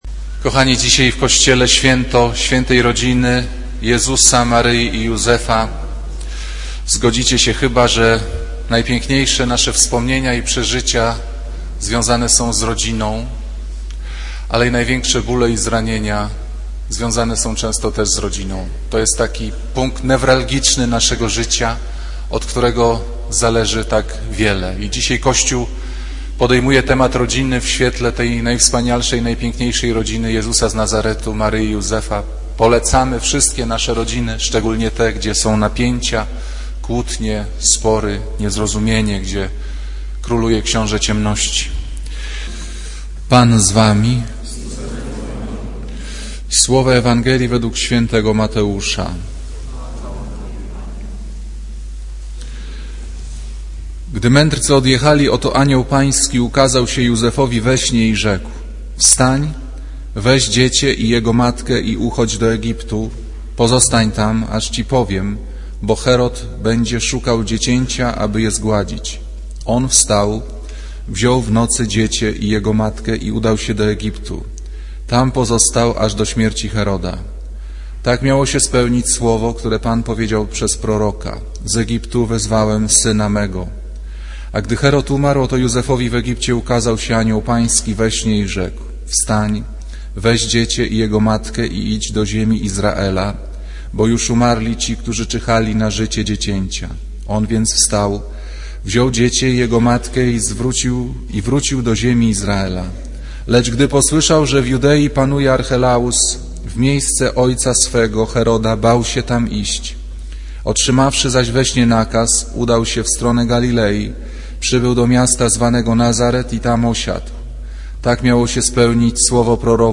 Kazanie z 30 grudnia 2007r.
Piotra Pawlukiewicza // niedziela, godzina 15:00, kościół św. Anny w Warszawie « Kazanie z 2 grudnia 2007r.